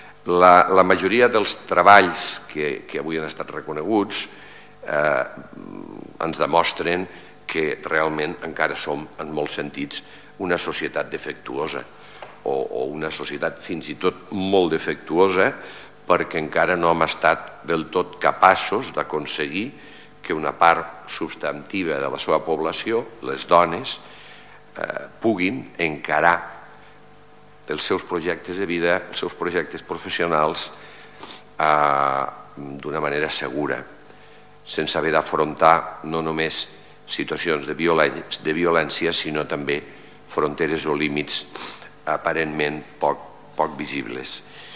tall-de-veu-de-lalcalde-miquel-pueyo-sobre-els-premis-mila-de-periodisme-i-la-beca-cristina-de-pizan